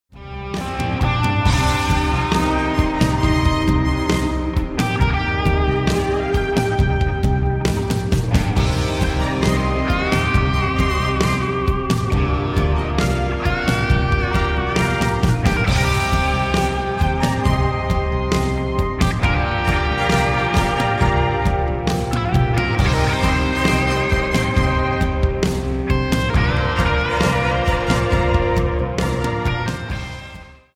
Рингтоны Без Слов » # Рингтоны Из Игр